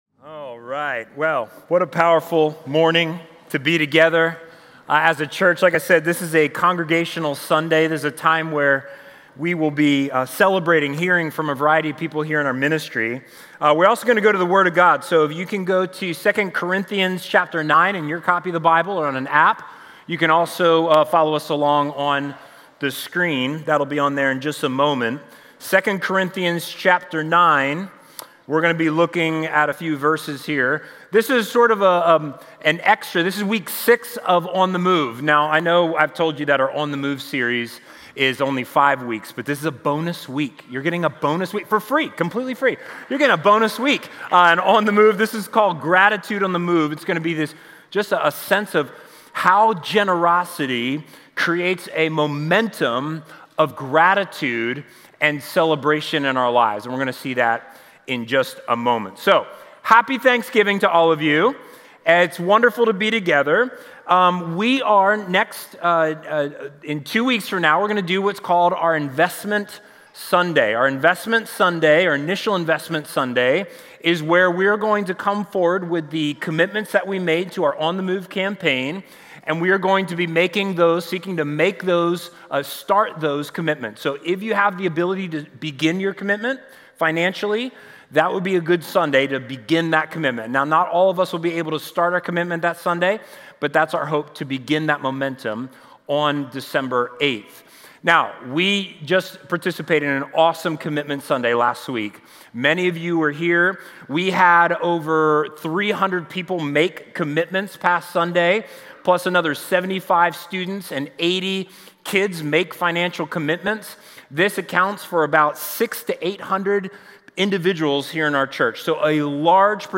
This is our semi-annual Congregational Sunday.